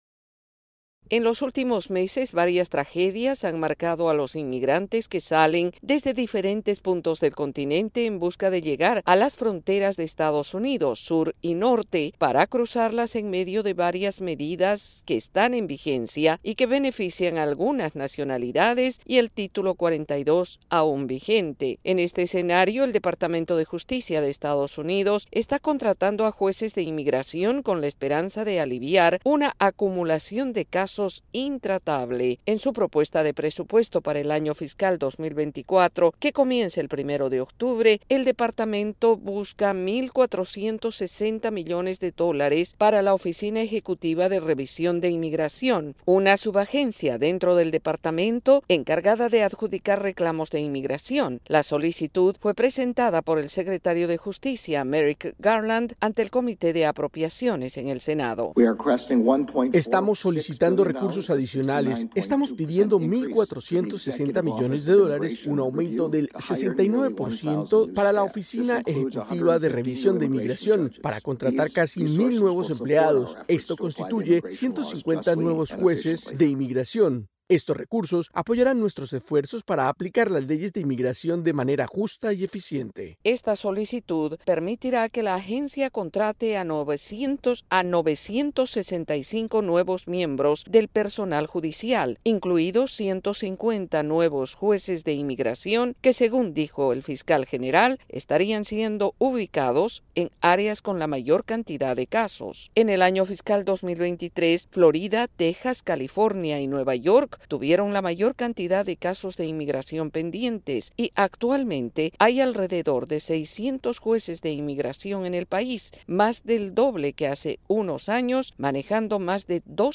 desde la Voz de América en Washington DC.